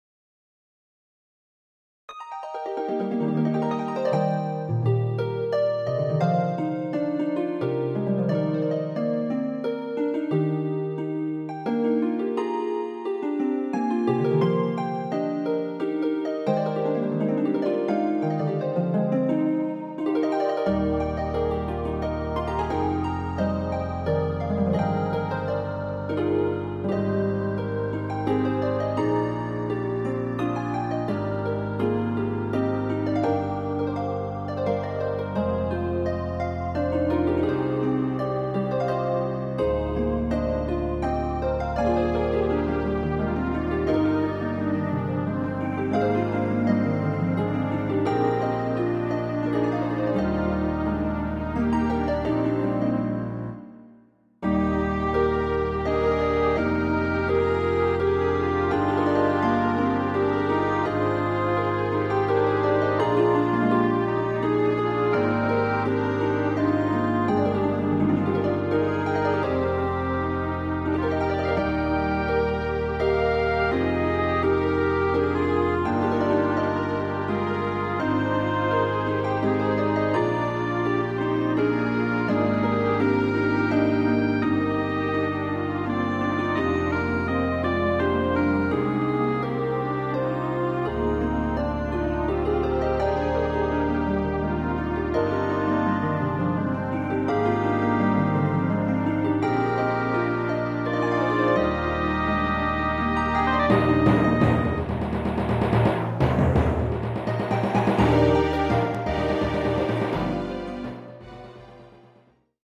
春の風のように舞うハープの心地よい音色、そして暖かな空気のようなストリングスの音――春の到来と喜びを感じる曲ですね。